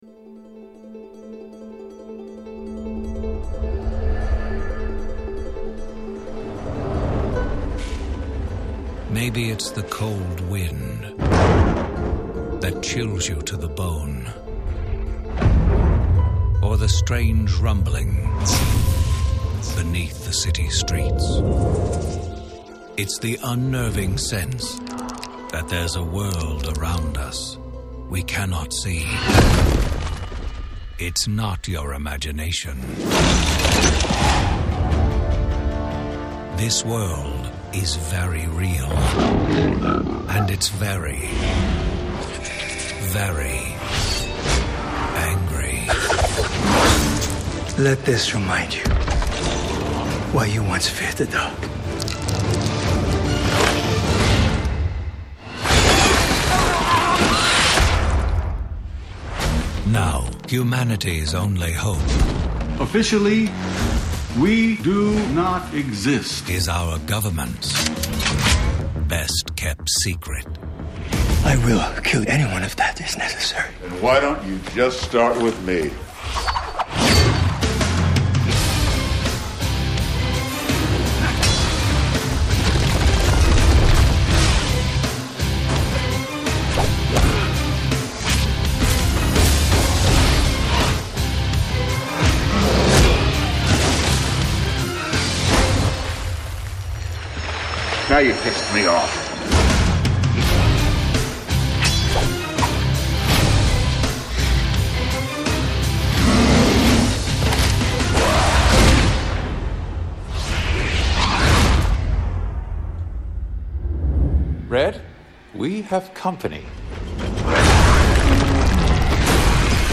Hellboy 2 trailer